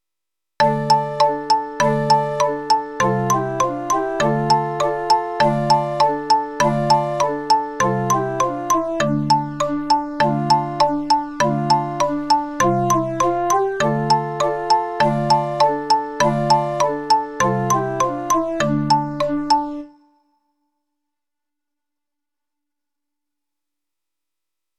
Popular de Hungría, flautas a dos voces y xilófonos.